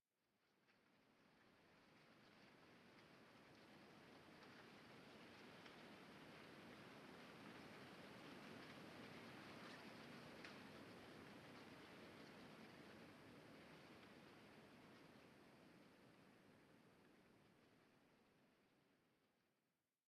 Minecraft Version Minecraft Version latest Latest Release | Latest Snapshot latest / assets / minecraft / sounds / ambient / nether / crimson_forest / particles1.ogg Compare With Compare With Latest Release | Latest Snapshot